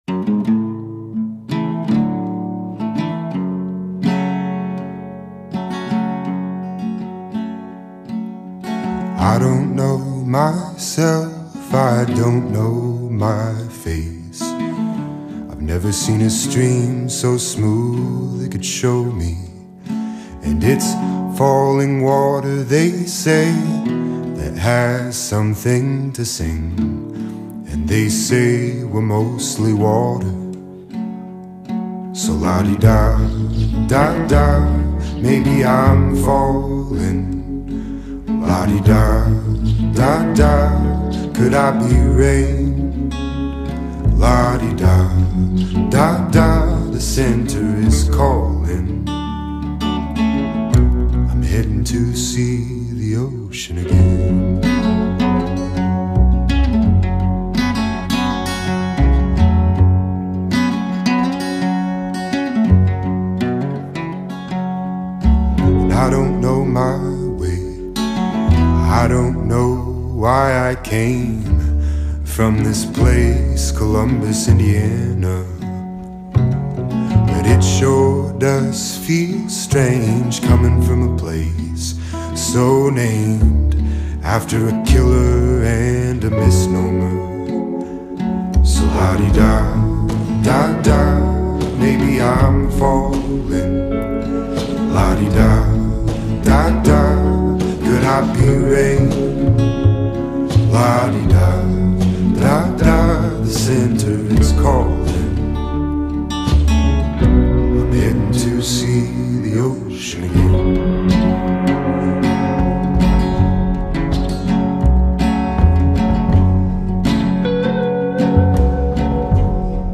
falling-water.mp3